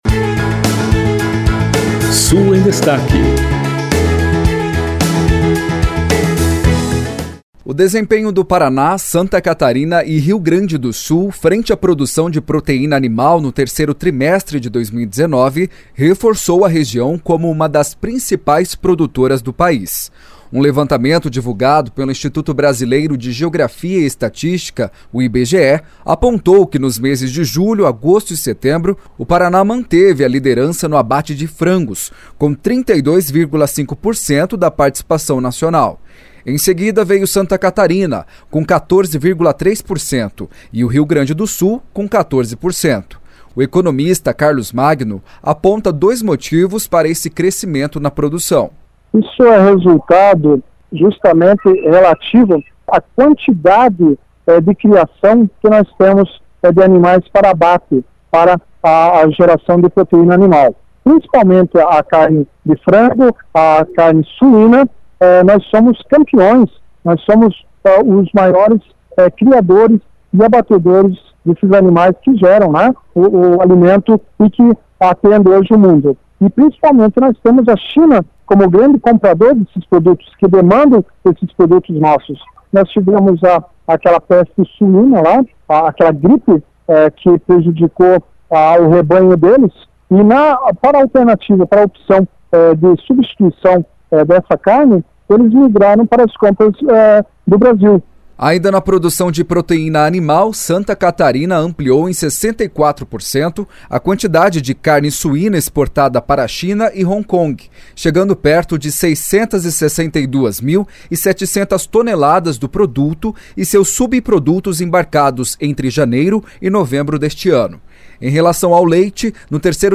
O secretário da Agricultura e Abastecimento do Paraná, Norberto Ortigara, destaca que essa onda de crescimento na produção de proteína animal traz com ela a geração de empregos e desenvolvimento econômico para a região sul do país.